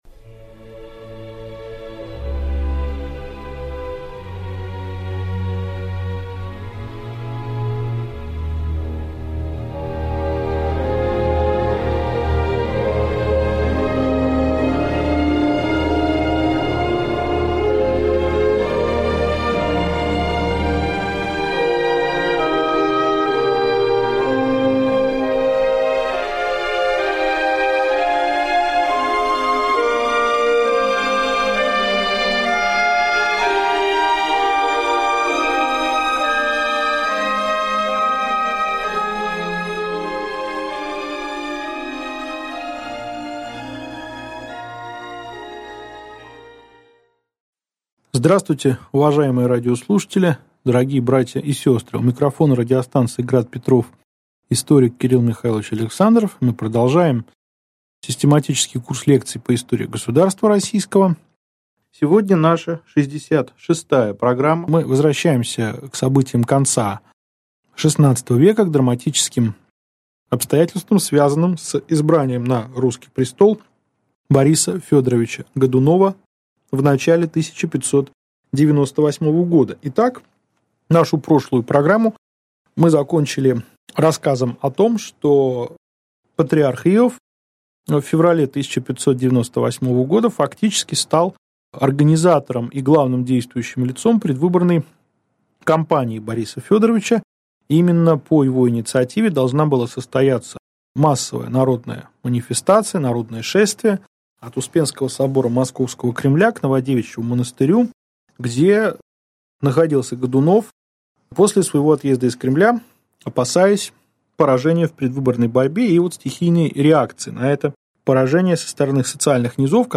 Аудиокнига Лекция 66. Избрание Бориса Годунова на царство | Библиотека аудиокниг